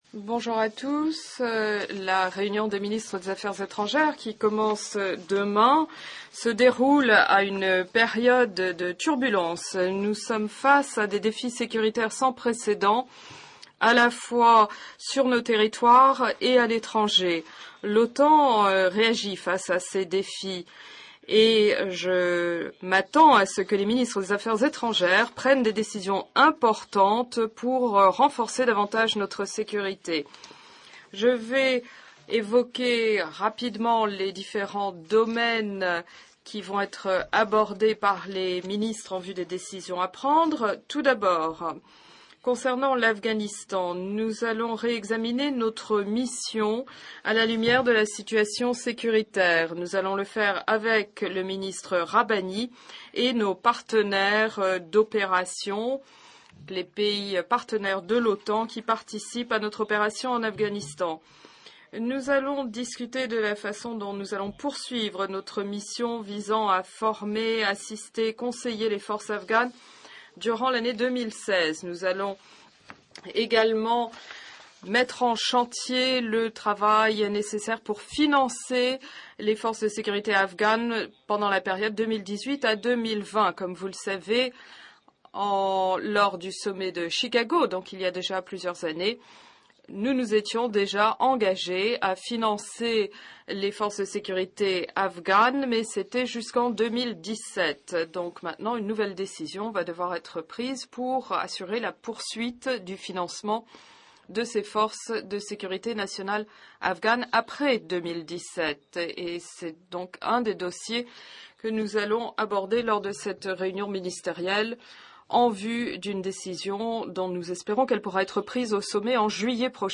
Pre-ministerial press conference by NATO Secretary General Jens Stoltenberg